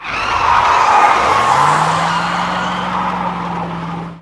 skid1.ogg